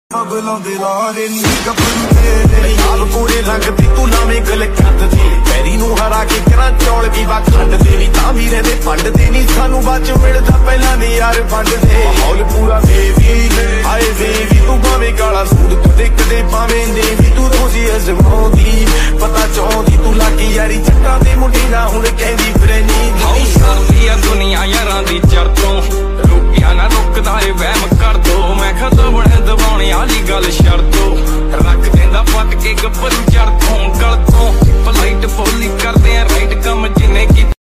Speed Boat In Attabad Lake Sound Effects Free Download